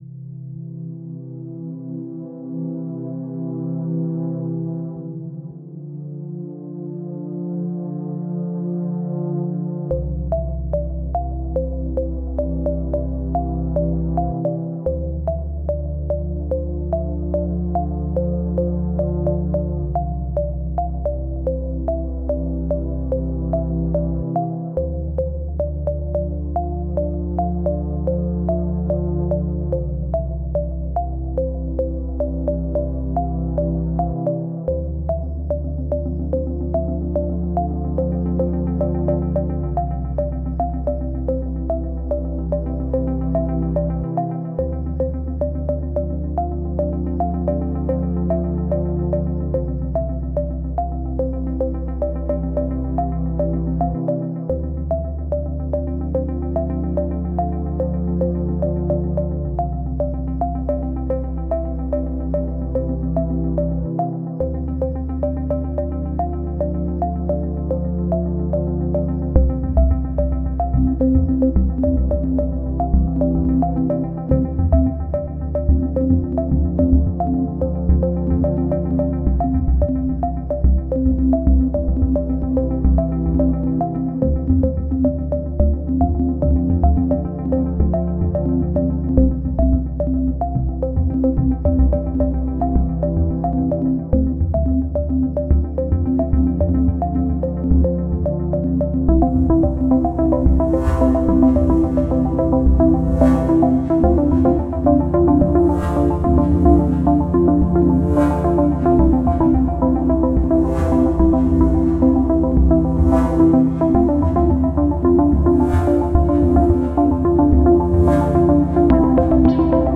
ambient atmospheric chill chillout classical deep downtempo drone sound effect free sound royalty free Nature